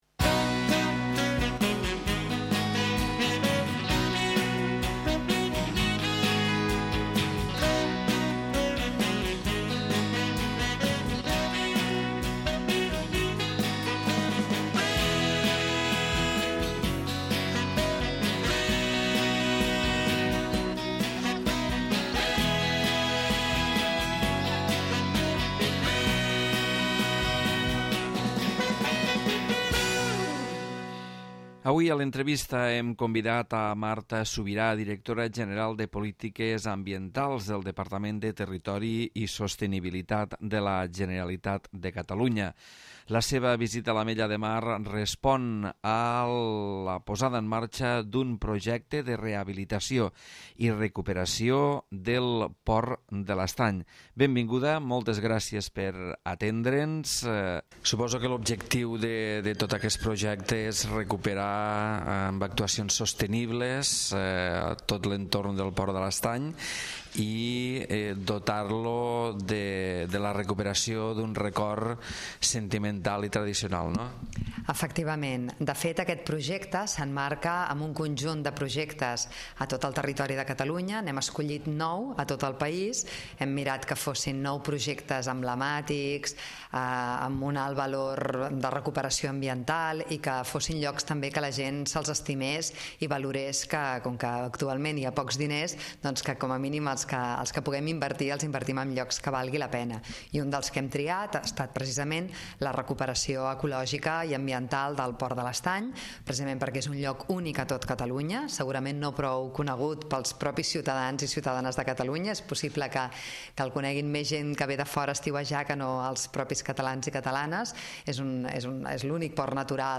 L'Entrevista
Marta Subirà, Directora General de Polítiques Ambientals del Departament de Territori i Sostenibilitat, parla a l'Entrevista del projecte de Recuperació del Port de l'Estany.